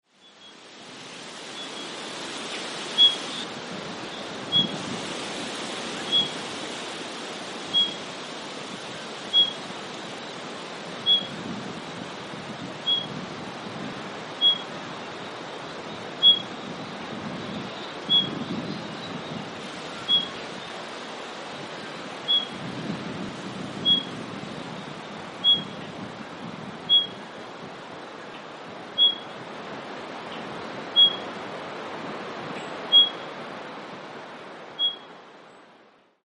Spotted Pardalote - Pardalotus punctatus
Voice: a soft but penetrating, slow, three-syllable call, 'sleep baby'; sometimes just the last two syllables or single plaintive note.
Call 1: single note repeated.
Spot_Pardalote.mp3